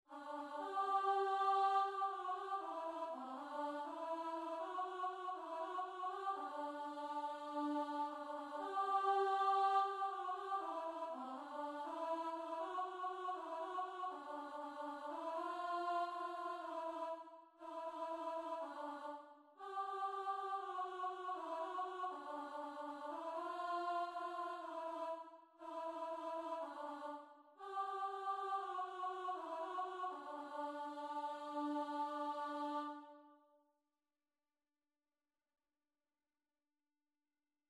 Free Sheet music for Choir (SATB)
Joyfully = c.120
G major (Sounding Pitch) (View more G major Music for Choir )